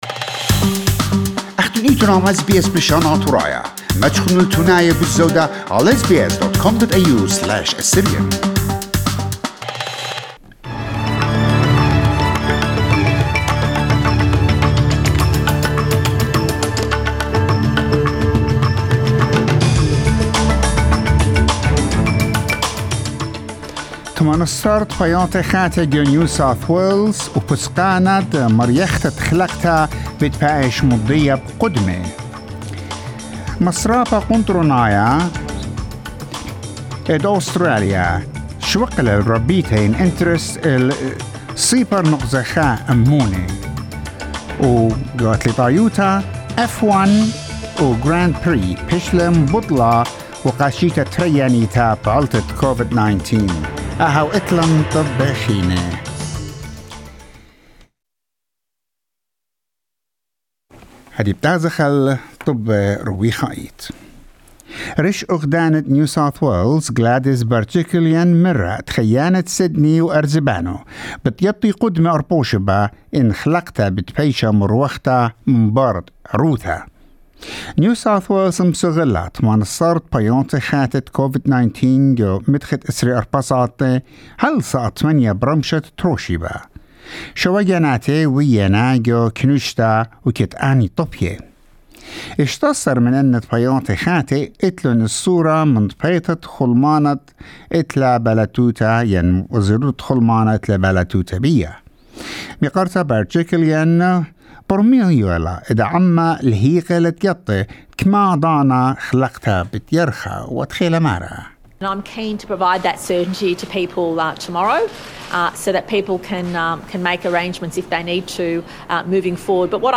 SBS News Bulletin in Assyrian 6 July 2021